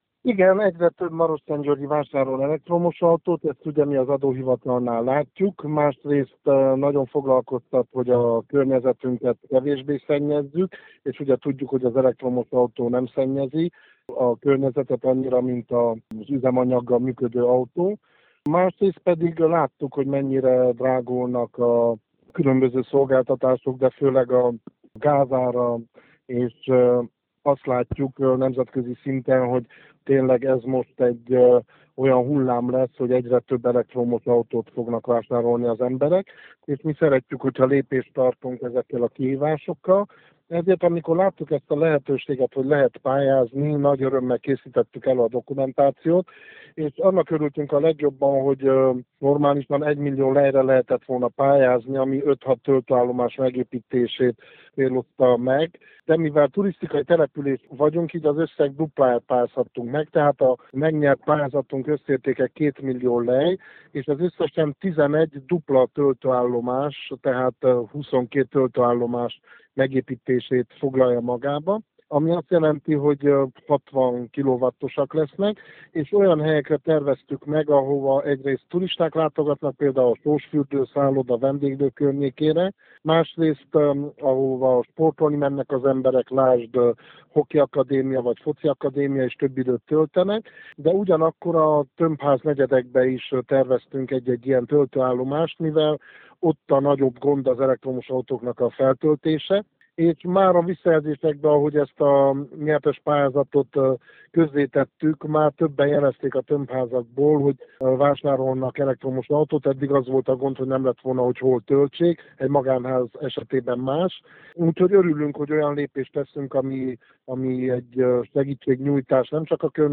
Sófalvi Szabolcs polgármester elmondta, hogy 2 millió lejre pályáztak, ebből 11 elektromos autó töltőállomást tudnak létrehozni.